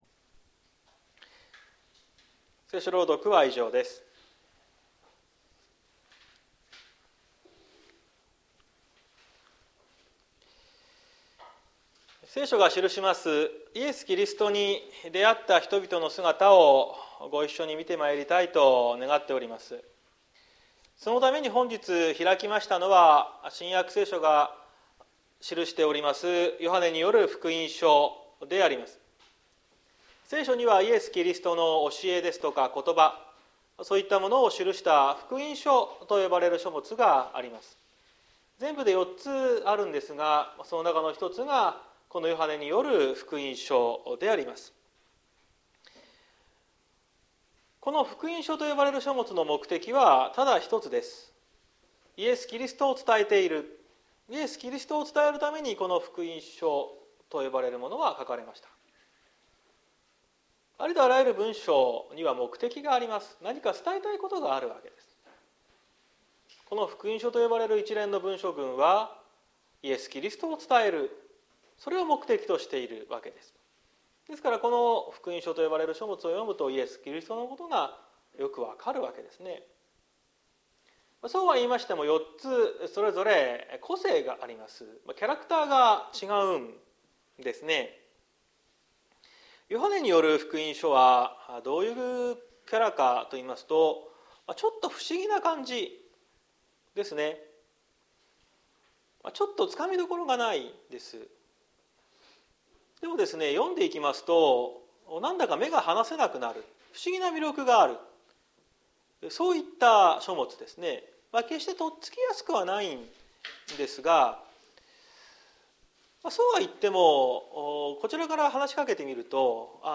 2023年02月26日朝の礼拝「上から目線と神目線」綱島教会
説教アーカイブ。
毎週日曜日の10時30分から神様に祈りと感謝をささげる礼拝を開いています。